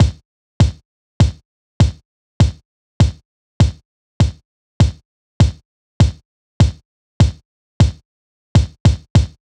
Unison Funk - 4 - 100bpm - Kick.wav